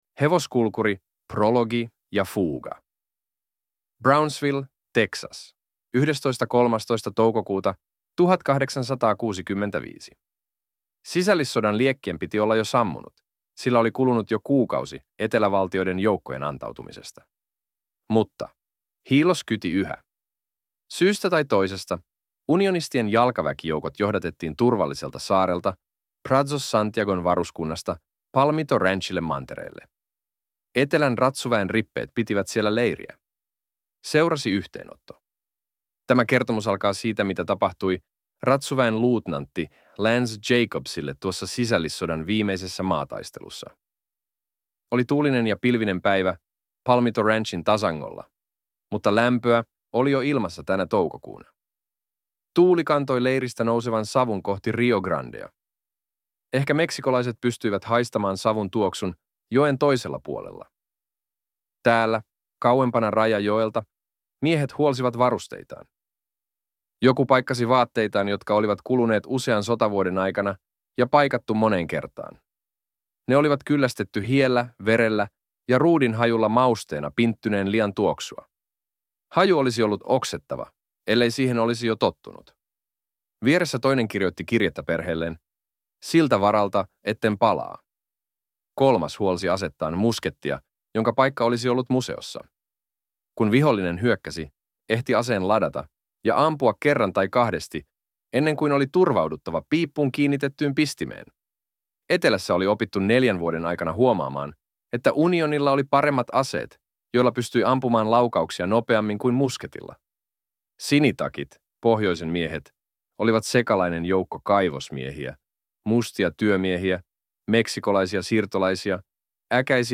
Audiokirja